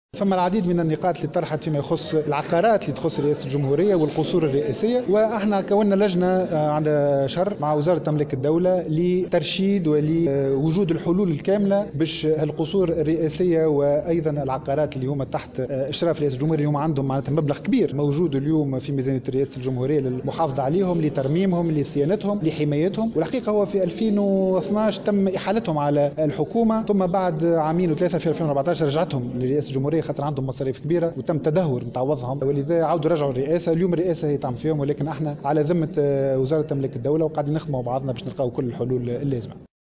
و بيّن "العزابي" في تصريح لمراسلة الجوهرة أف أم خلال حضوره اليوم جلسة استماع له من قبل أعضاء لجنة الحقوق و الحرّيات و العلاقات الخارجية حول مشروع ميزانية رئاسة الجمهورية لسنة 2018 ، أنّ الهدف من إحداث هذه اللجنة ترشيد و إيجاد الحلول لفائدة هذه العقارات و القُصور الرئاسية التي تتطلب عمليات ترميمها و المحافظة عليها و صيانتها مبالغ مُهمّة يتم رصدها صلب ميزانية رئاسة الجمهورية، خاصة بعد "حالة التدهور" التي لحقت بها جراء إحالتها منذ سنة 2012 على رئاسة الحكومة قبل أن تتم إحالتها مجددا على مصالح رئاسة الجمهورية حسب قوله.